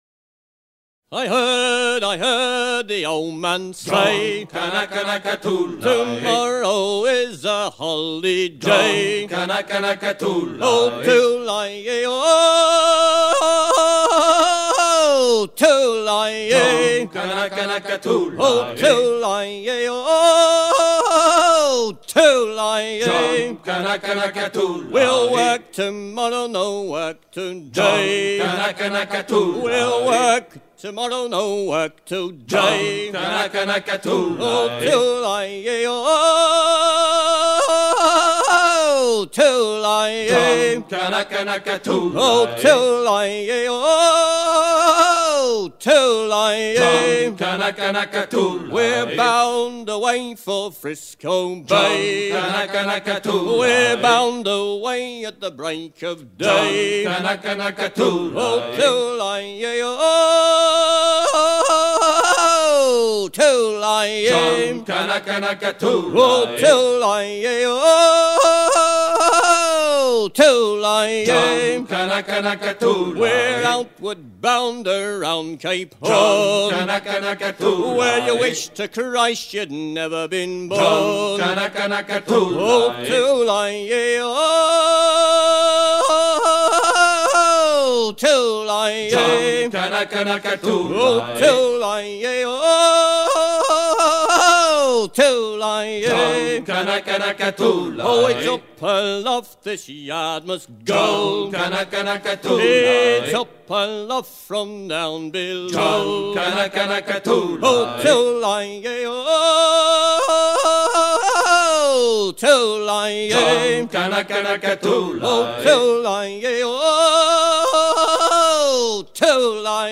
Genre laisse
Artiste de l'album Cabestan
Edition discographique Chants de marins - Cabestan